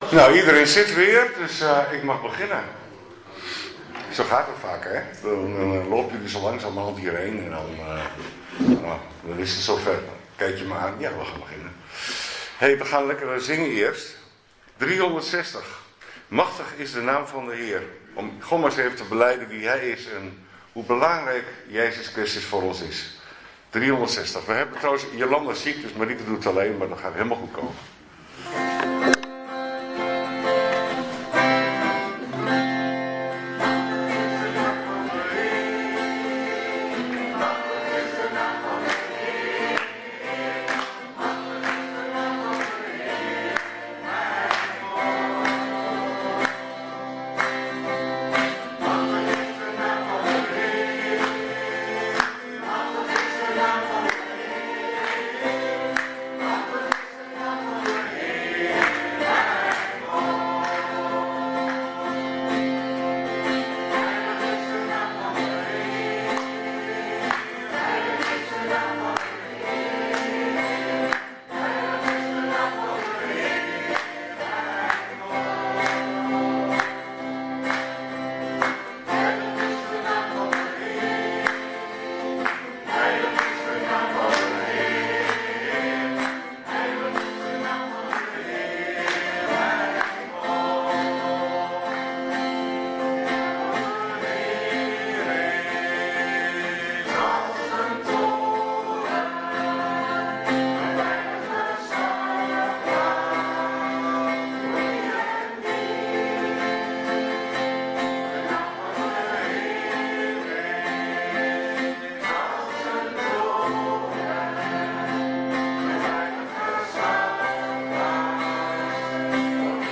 18 januari 2026 dienst - Volle Evangelie Gemeente Enschede
Preek